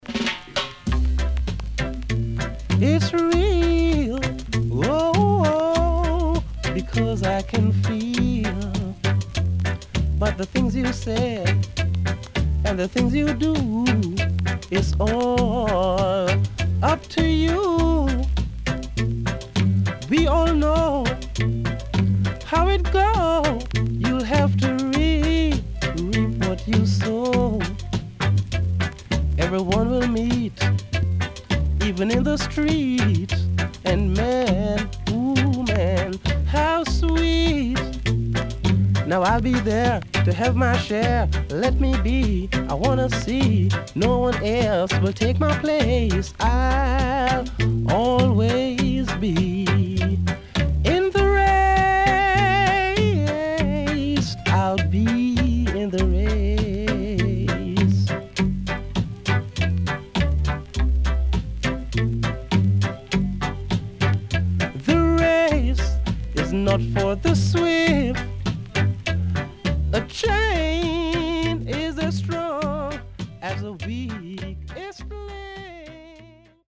HOME > REGGAE / ROOTS
W-Side Good Vocal.Pressnoise.Small Hiss
SIDE A:プレスノイズ入ります。軽いヒスノイズ入ります。